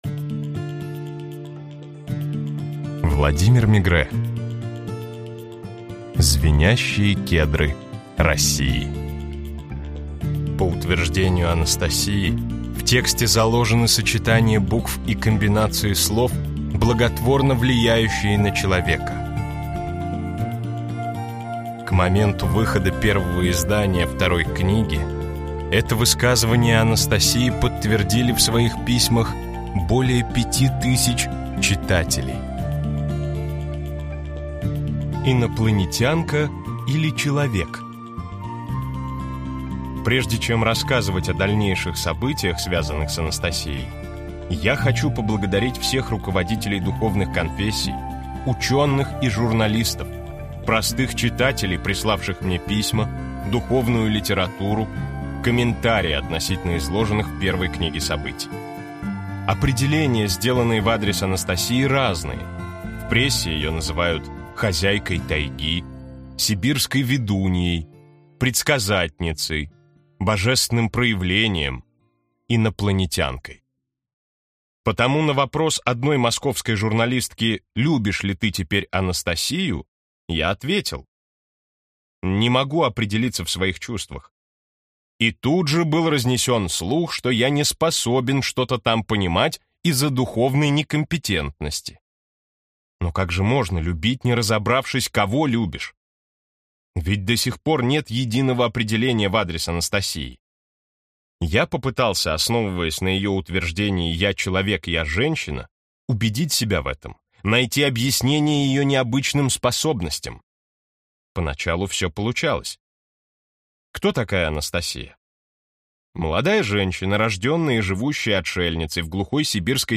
Аудиокнига Звенящие кедры России. Второе издание | Библиотека аудиокниг